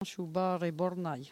Patois
Locution